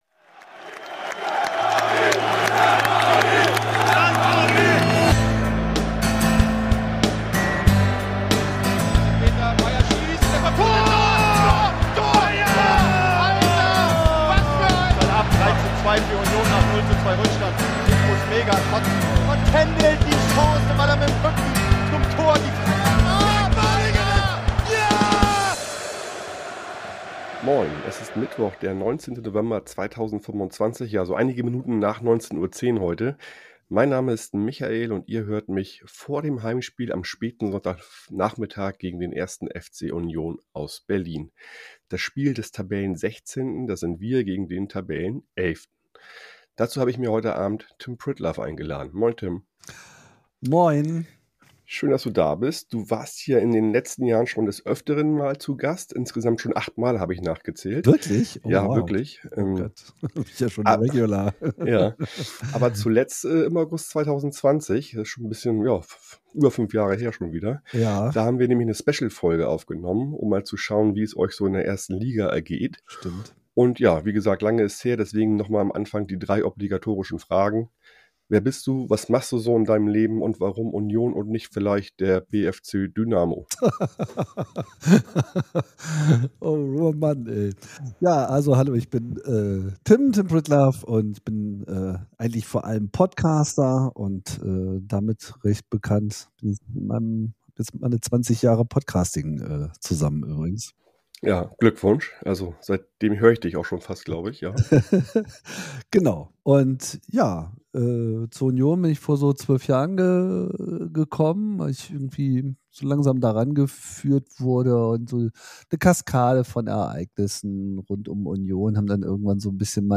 Im Studio 1 des Bayerischen Rundfunks in München fand eine musikalische Begegnung der Generationen statt. Die BRSO-Orchesterakademie, also Orchester-Azubis, haben ein tolles Konzert zusammen mit ihrem Schirmherr Sir Simon Rattle gespielt.